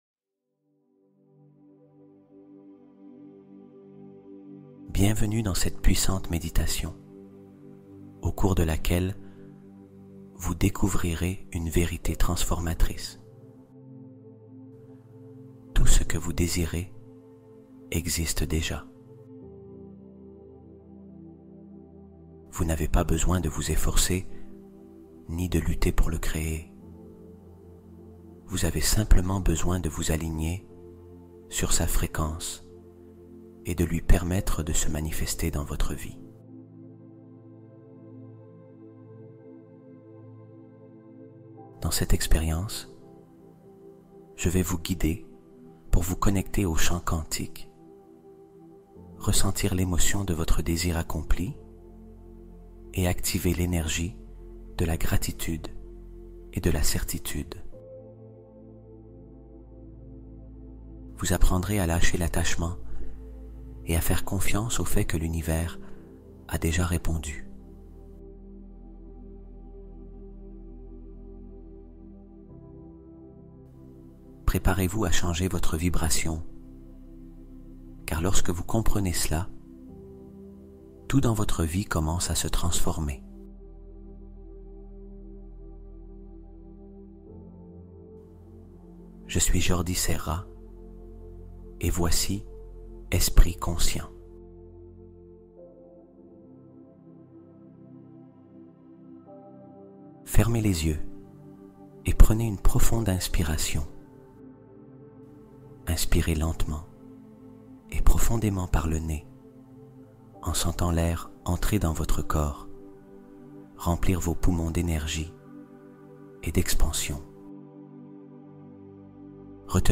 Retrouve ta confiance avec cette méditation puissante